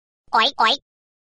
Звуки сообщений
На данной странице вы можете прослушать онлайн короткие звуки уведомлений для sms на  телефон android, iPhone и приложения.